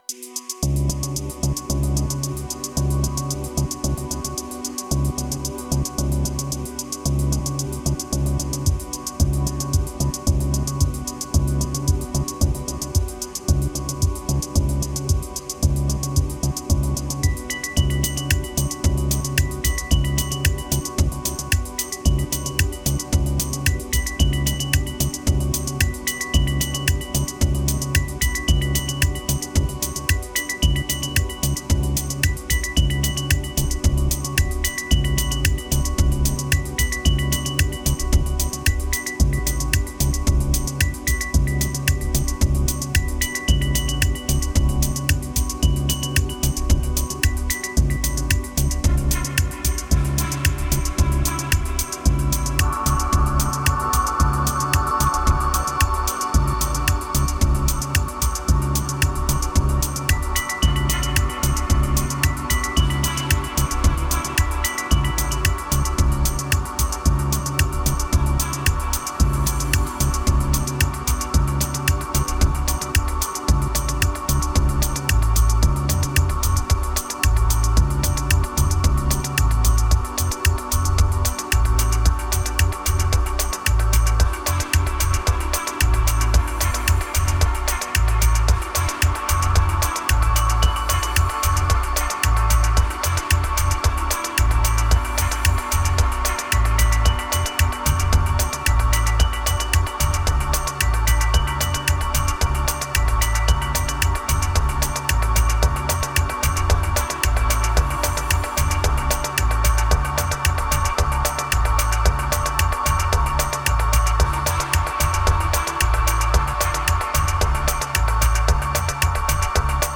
Tanz Kicks Deep House Dark Epic